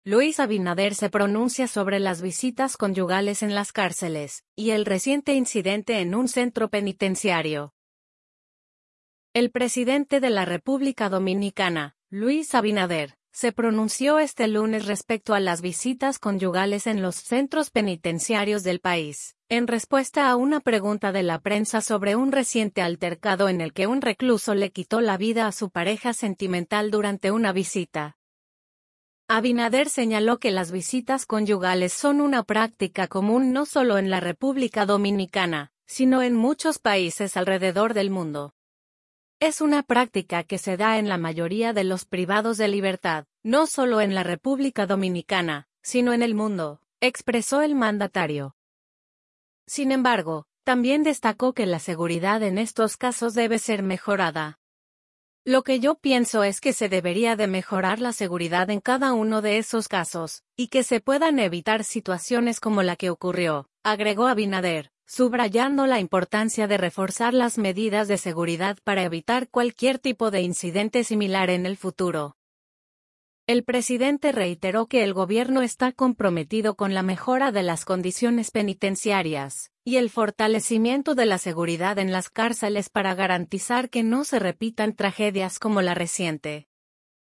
El presidente de la República Dominicana, Luis Abinader, se pronunció este lunes respecto a las visitas conyugales en los centros penitenciarios del país, en respuesta a una pregunta de la prensa sobre un reciente altercado en el que un recluso le quitó la vida a su pareja sentimental durante una visita.